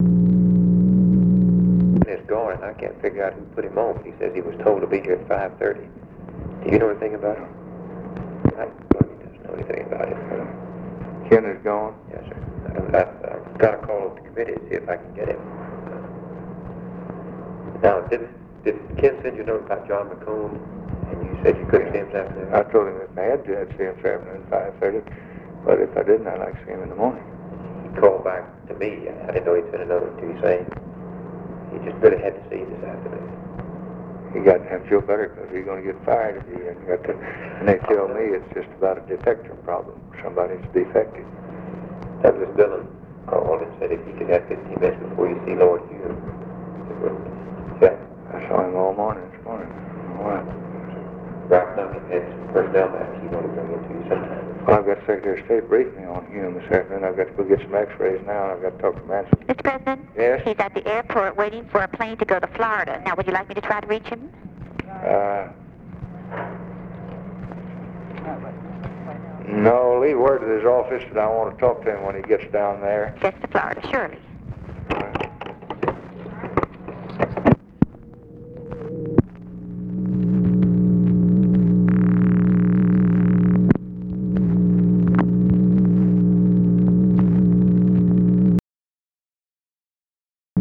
LBJ DISCUSSES HIS SCHEDULE WITH BILL MOYERS WHILE WAITING FOR TELEPHONE CALL TO UNIDENTIFIED PERSON TO BE PLACED
Conversation with TELEPHONE OPERATOR and OFFICE CONVERSATION
Secret White House Tapes